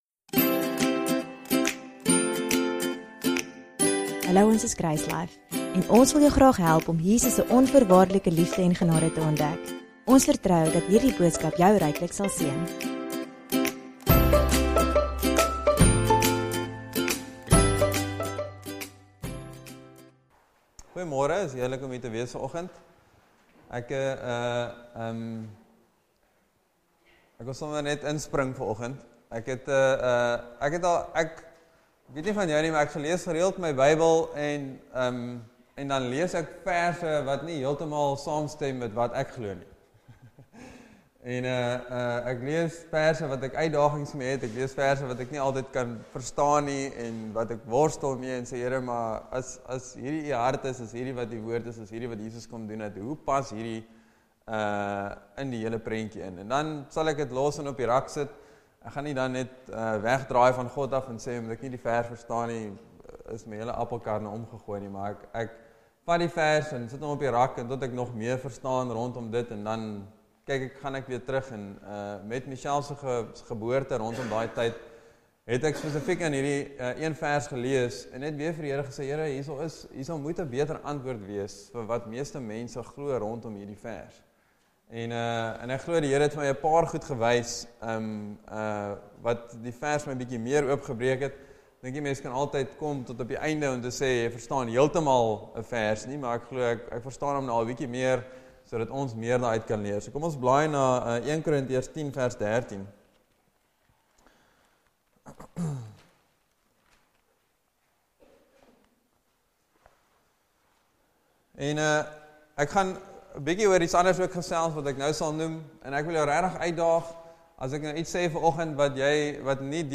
DOWNLOAD READ MORE Sermon Test Category